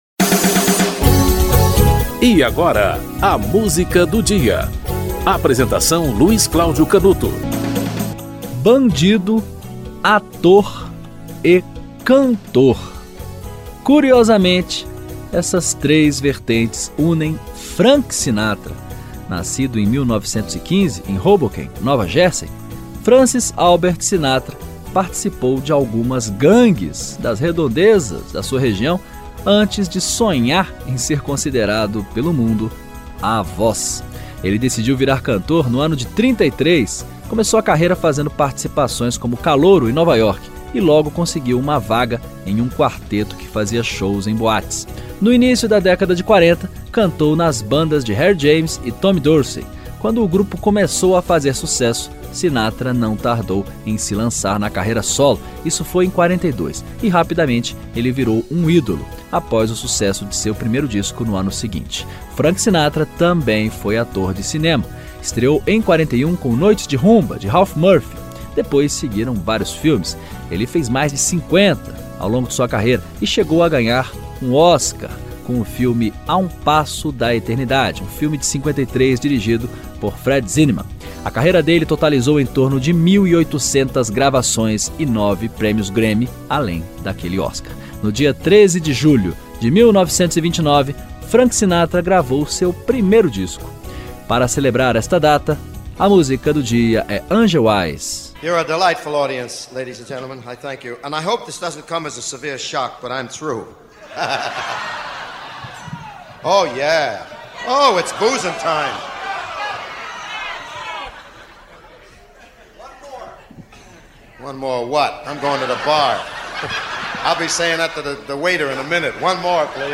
Frank Sinatra - Angel Eyes (Matt Dennis, Earl Brent)
O programa apresenta, diariamente, uma música para "ilustrar" um fato histórico ou curioso que ocorreu naquele dia ao longo da História.